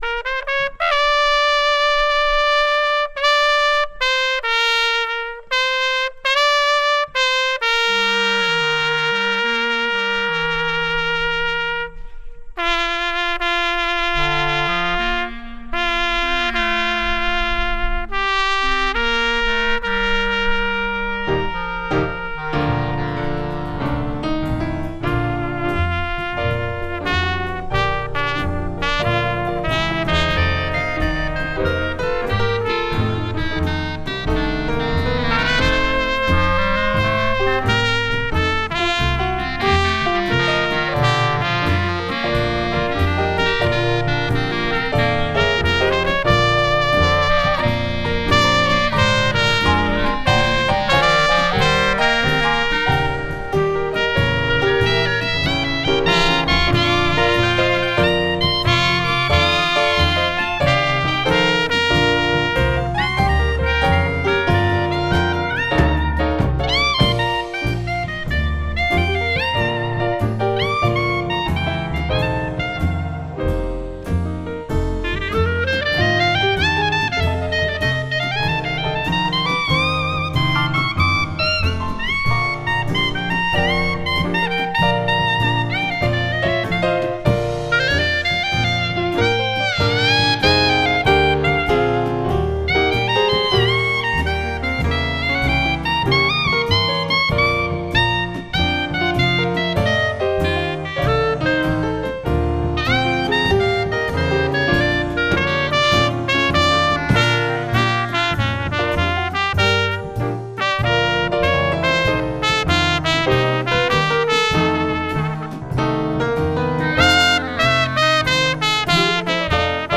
Summer Ecumenical Service
You can also enjoy these beautiful performances from the Occasional Jazz Ensemble, recorded during the service.